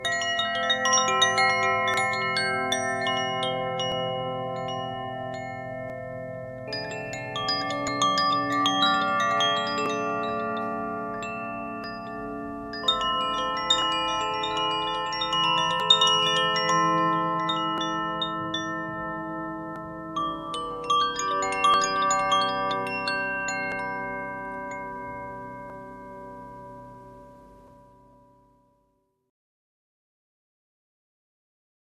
• Terra (Země): G C E F G C E G
• Aqua (Voda): A D F G A D F A (pentatonická)
• Ignis (Oheň): G B D G B D G (pentatonická)
Každá zvonkohra má osm tónů a vyznačuje se magickým timbrem.
Jsou oblíbené pro svůj čistý, harmonický zvuk a schopnost vytvářet uklidňující zvukovou krajinu.